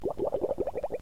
Sound Effects
Pom Pom's bubble noises Homestar gargling with Listerine, The USS Flirtini sinking See for a list of all toons, Date Nite
Pom_Pom_Bubbling.mp3